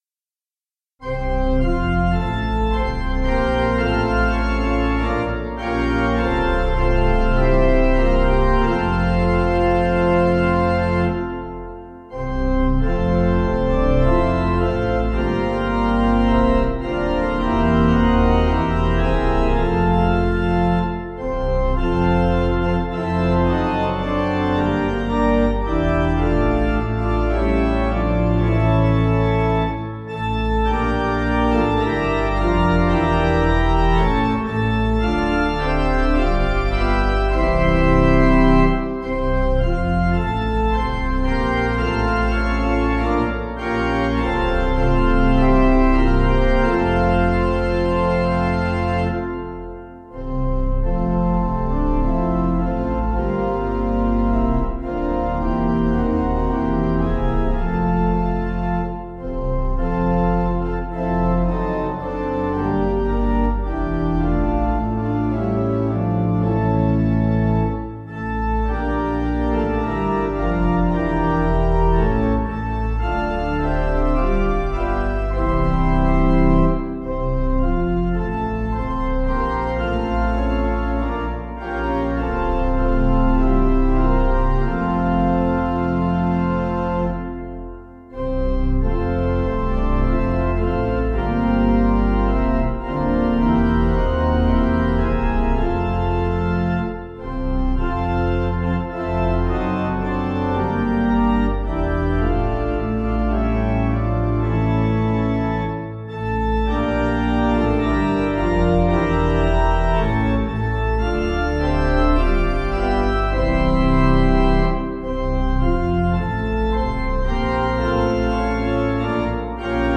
Tune: PATMOS (Storer)
Information about the hymn tune PATMOS (Storer).